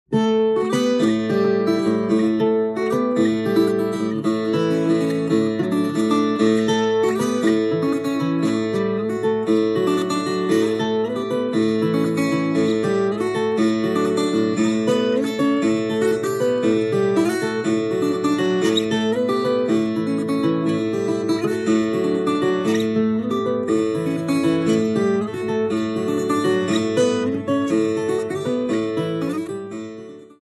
Viola
Instrumento de corda cujo som é obtido pelo dedilhar das cinco ou seis cordas duplas que dão maior sonoridade ao instrumento, de formato semelhante ao do violão. Tem papel importante no acompanhamento musical da folia.
viola.mp3